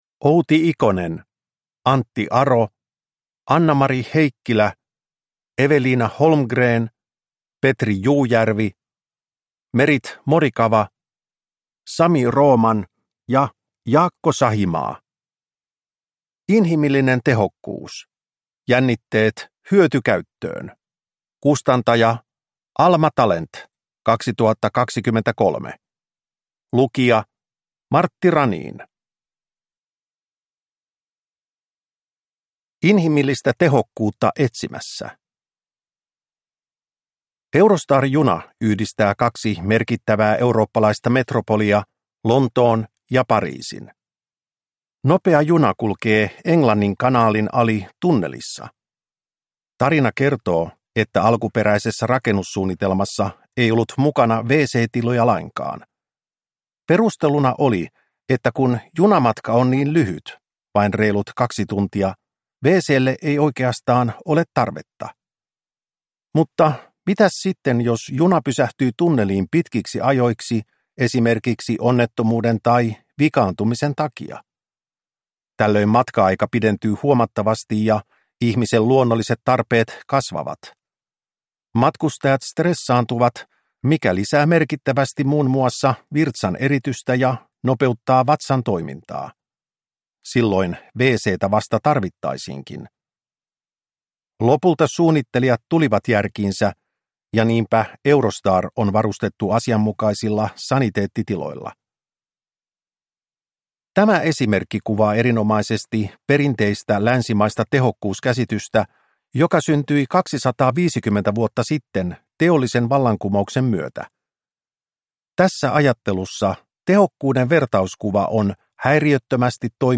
Inhimillinen tehokkuus – Ljudbok – Laddas ner